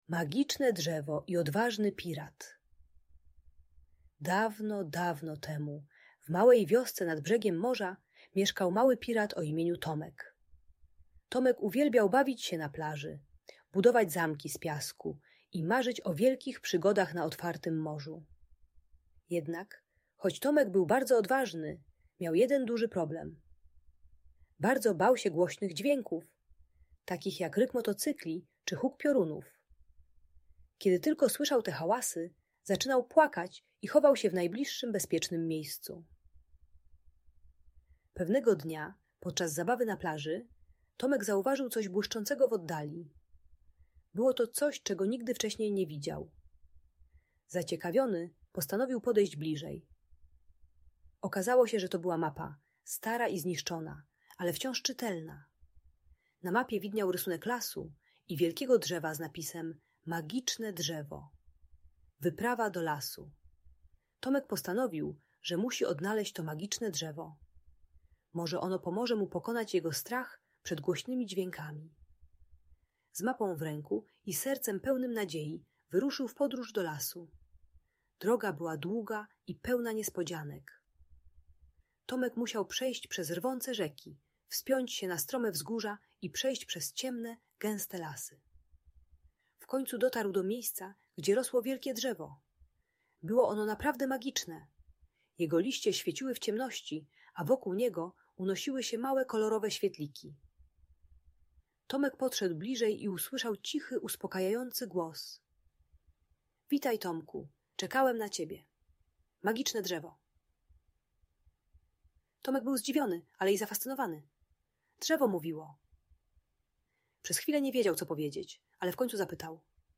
Magiczne Drzewo i Odważny Pirat - story dla dzieci - Audiobajka